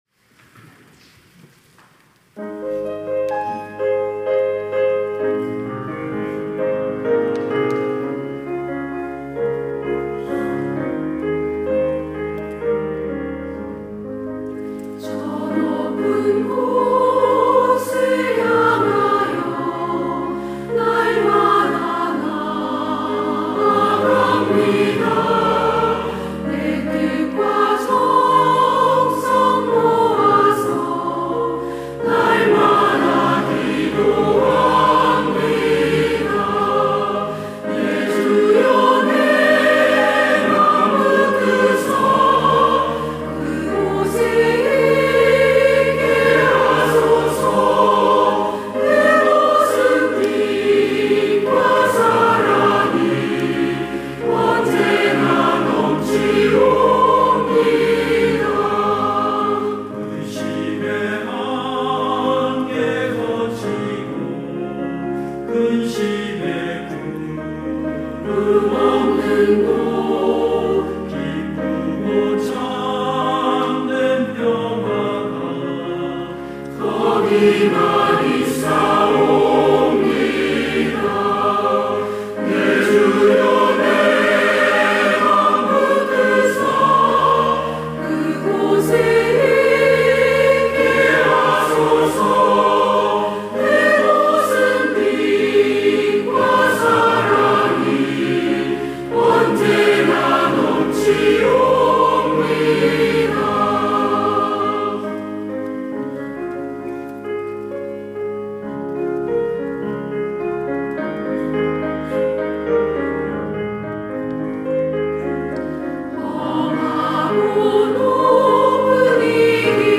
시온(주일1부) - 저 높은 곳을 향하여
찬양대